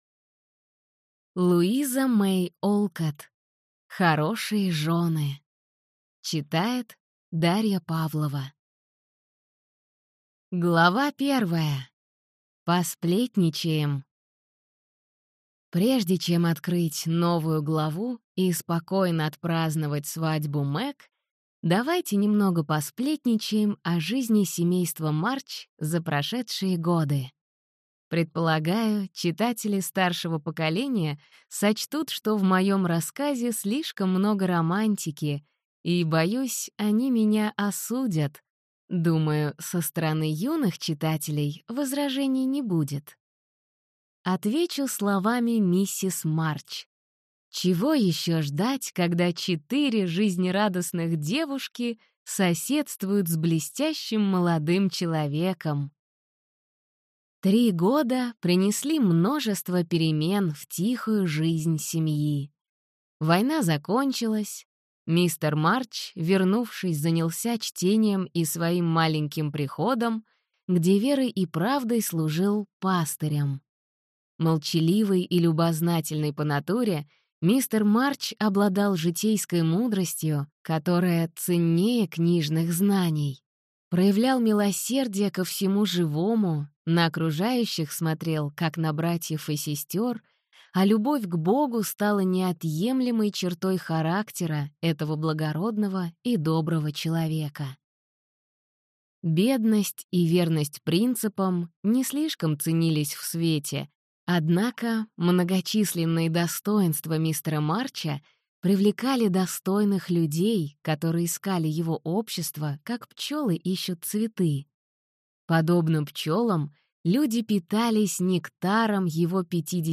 Аудиокнига Хорошие жены | Библиотека аудиокниг